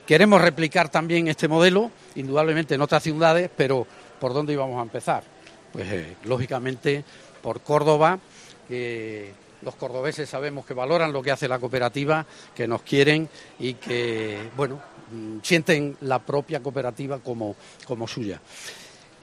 en la presentación del espacio D'Tapas